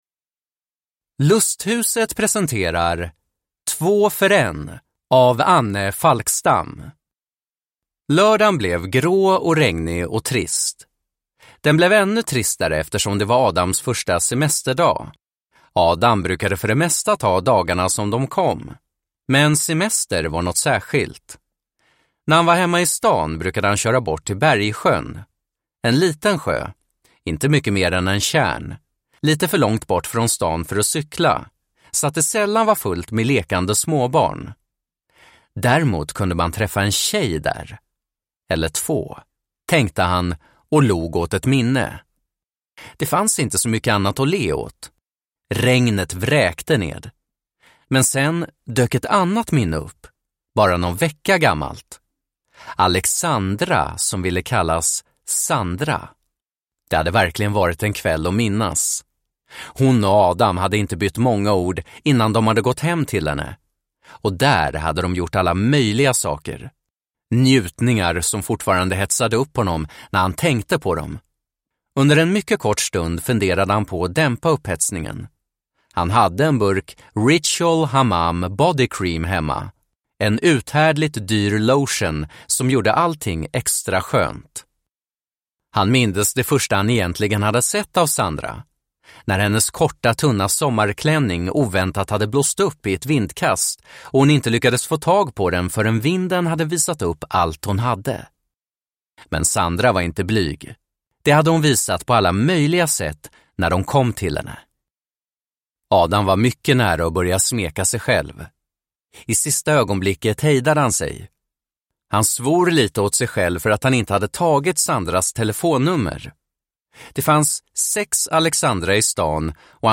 Två för en (ljudbok) av Anne Falkstam